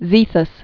(zēthəs)